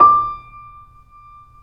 Index of /90_sSampleCDs/Roland L-CD701/KEY_YC7 Piano pp/KEY_pp YC7 Mono